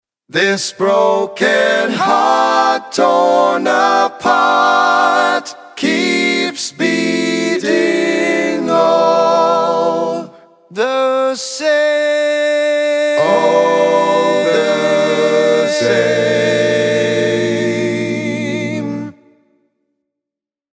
Key written in: A Minor
How many parts: 4
Type: Barbershop
All Parts mix: